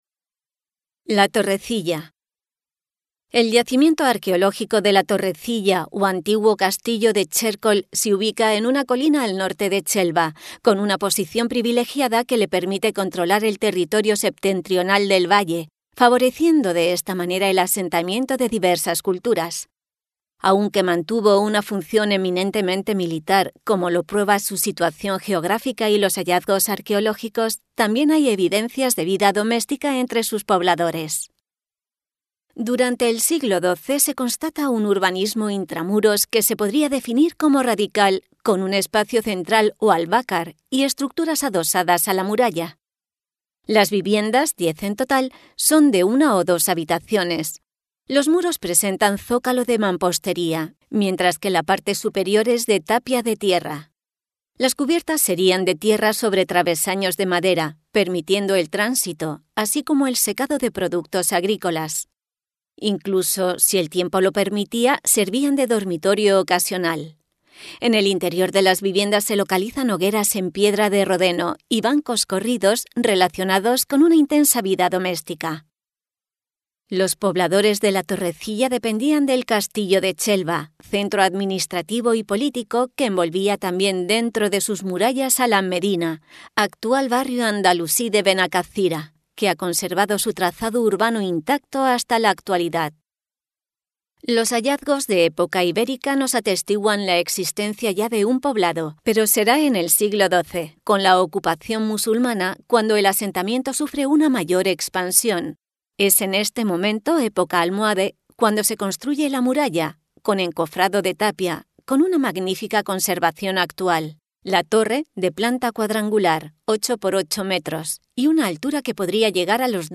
Audioguía La Torrecilla: